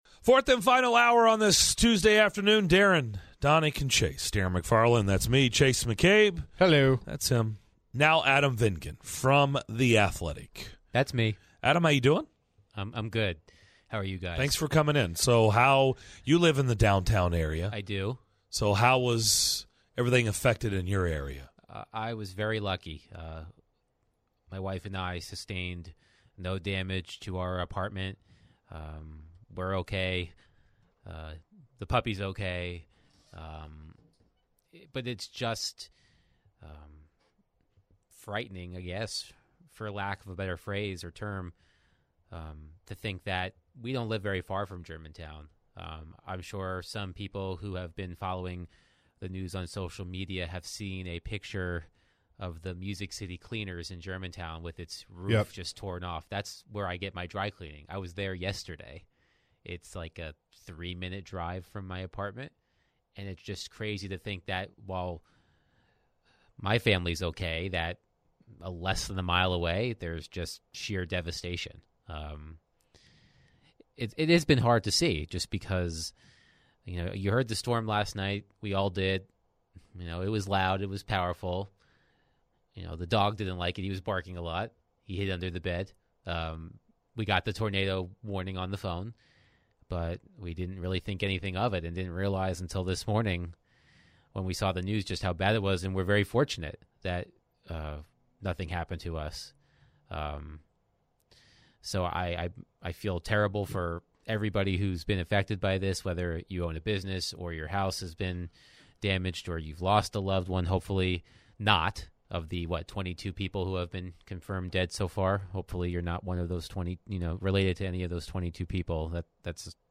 the guys are joined in studio